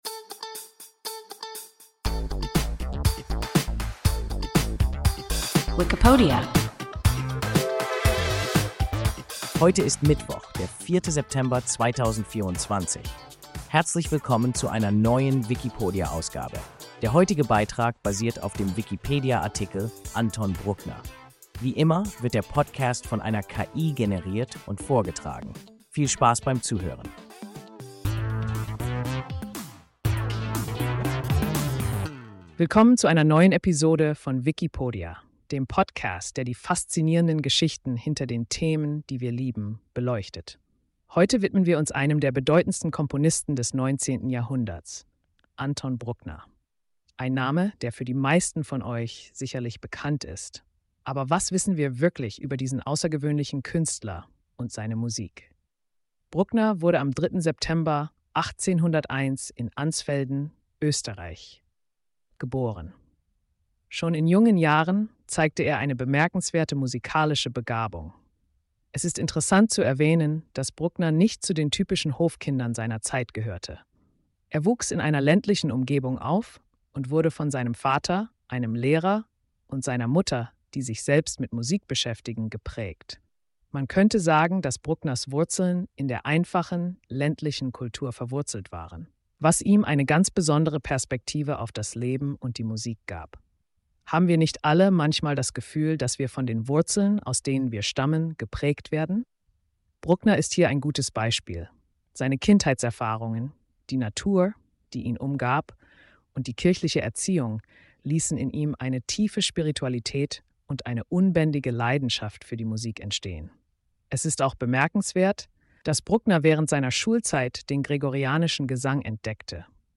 Anton Bruckner – WIKIPODIA – ein KI Podcast